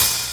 Treble-Heavy Open High-Hat E Key 31.wav
Royality free open high-hat tuned to the E note. Loudest frequency: 8231Hz
treble-heavy-open-high-hat-e-key-31-DeH.mp3